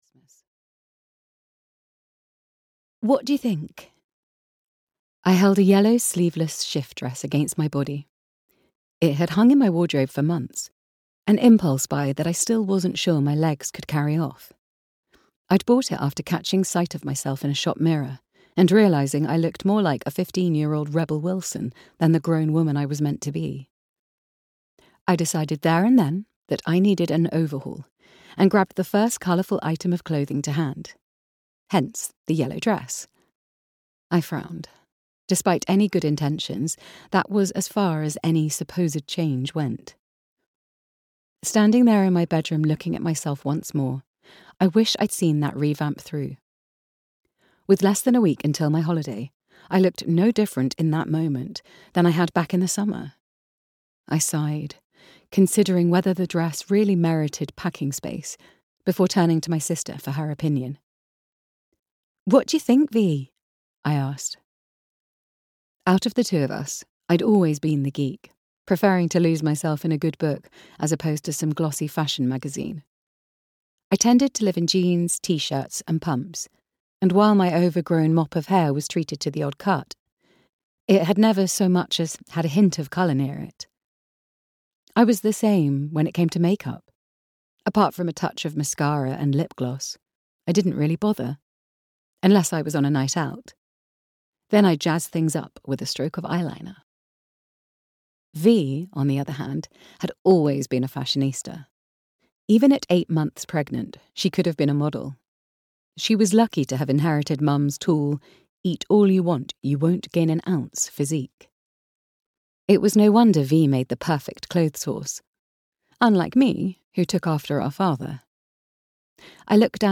Holly's Christmas Countdown (EN) audiokniha
Ukázka z knihy